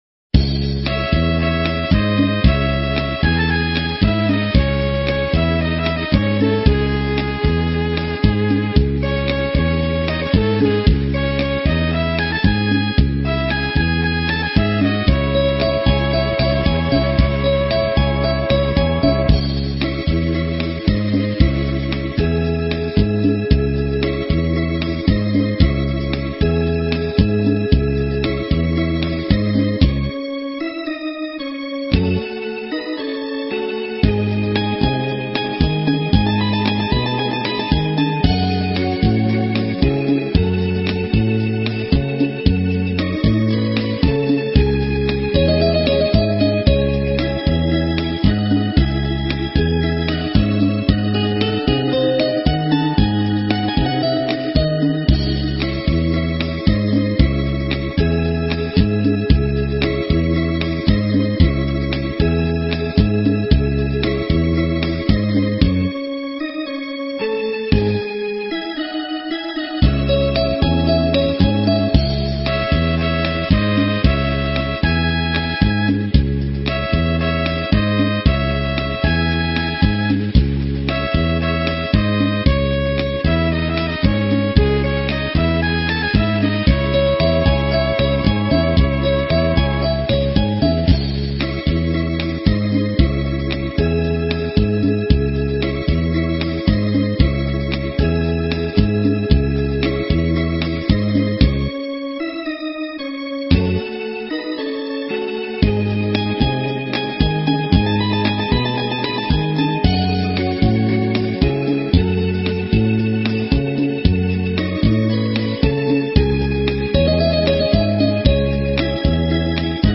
【★반주.명상.가사★】/♬~반주.연주.경음악